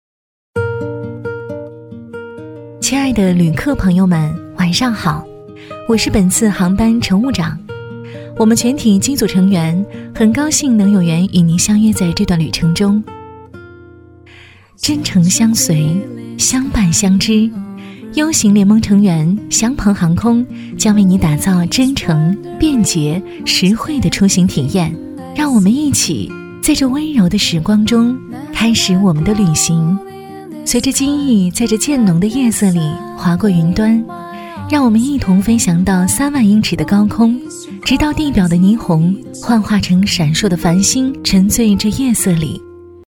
女16-机场广播【航空班机晚间播音-温馨柔和】
女16-机场广播【航空班机晚间播音-温馨柔和】.mp3